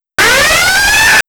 SWEET JESUS THAT IS LOUD.
However, it’s definitely the red alert sound.
redalert-bad.wav